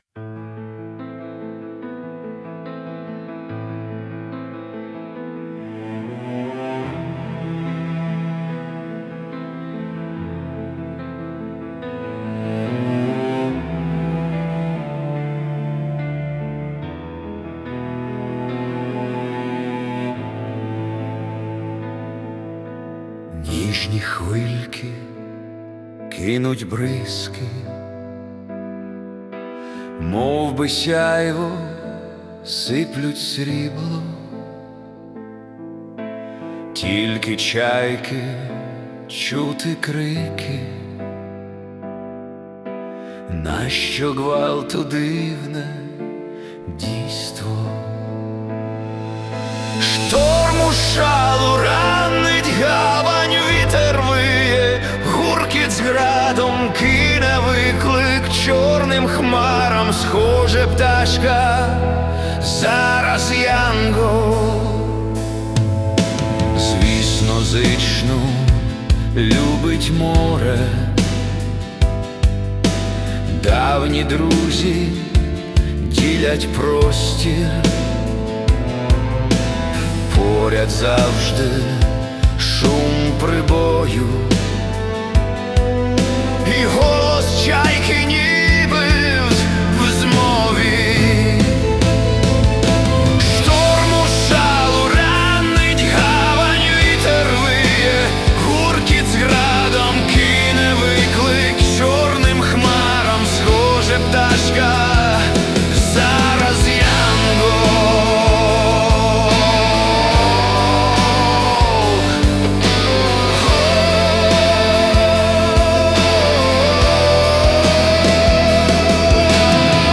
Музична композиція створена за допомогою SUNO AI
СТИЛЬОВІ ЖАНРИ: Ліричний
Майстерно підібрано музичне оформлення!